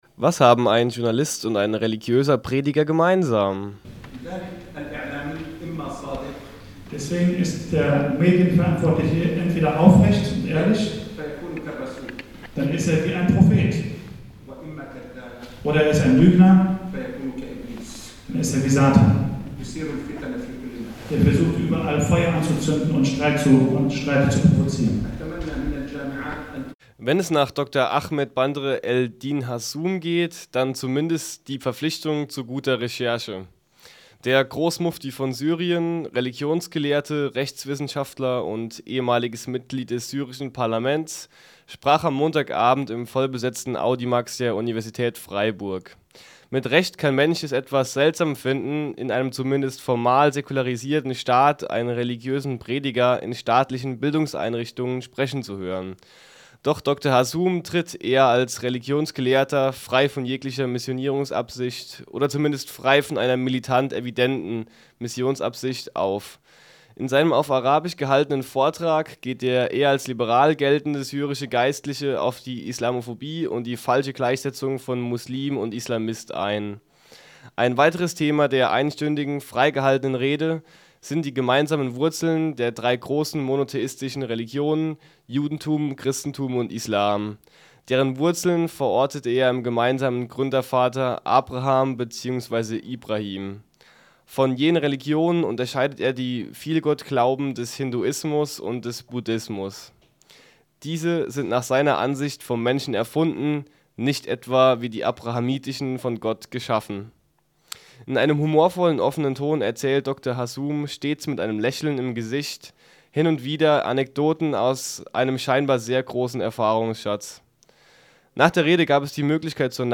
Vortrag des Großmuftis Syriens Bandr-El-Din Hassum